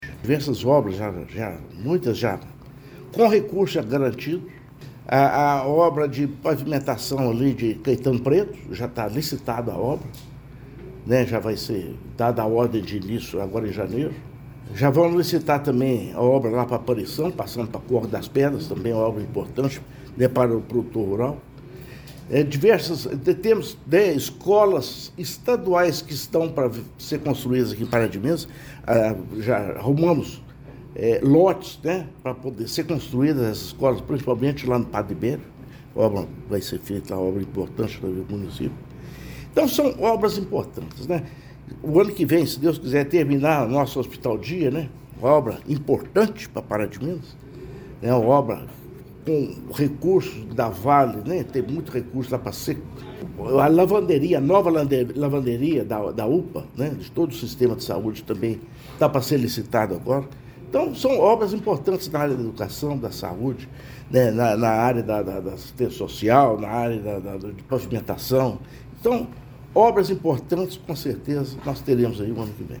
A Prefeitura de Pará de Minas apresentou, nesta segunda-feira, 22 de dezembro, um panorama das principais ações desenvolvidas ao longo de 2025, marcando o primeiro ano da atual administração.
O prefeito ressaltou que a gestão trabalha com planejamento e responsabilidade, buscando garantir melhorias em áreas essenciais e deixar bases sólidas para o futuro de Pará de Minas: